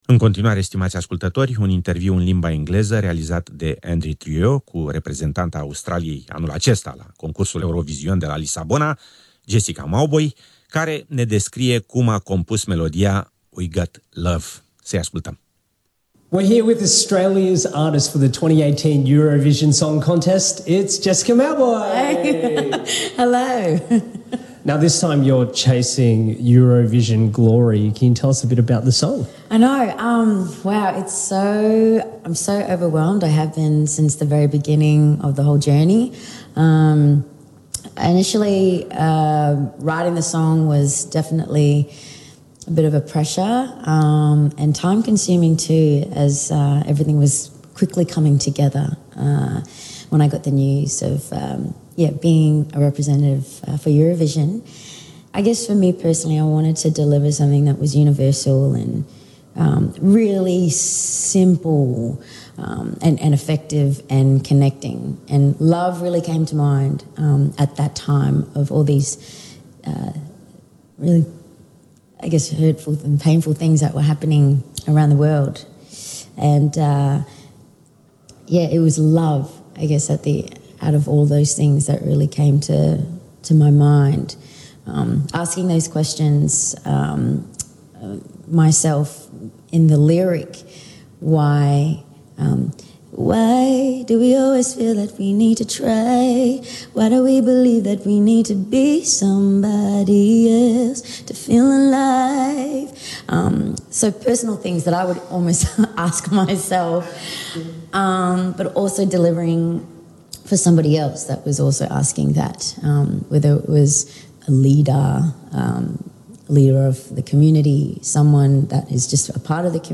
Andy Trieu interviu cu Jessica Mauboy
Interviu Andy Trieu cu Jessica Mauboy, reprezentanta Australiei la concursul de muzica Eurovizion 2018
interview_jessica_mauboy.mp3